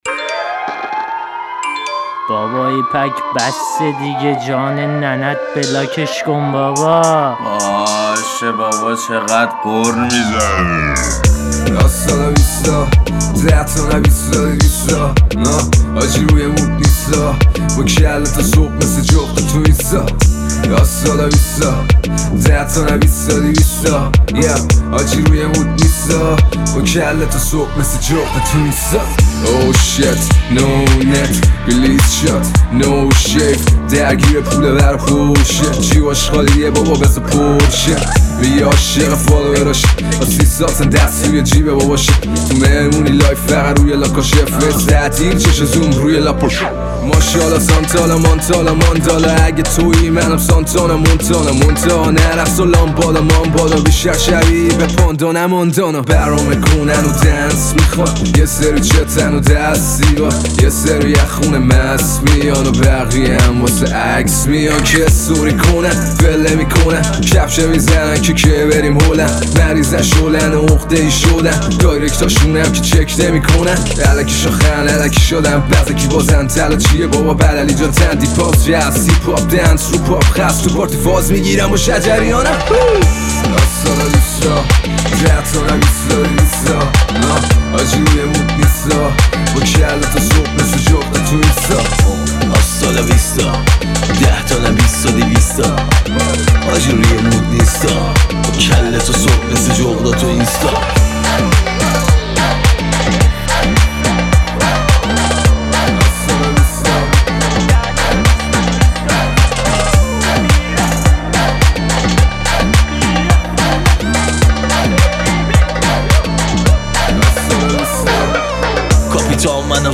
آهنگ جدید و شاد ۹۸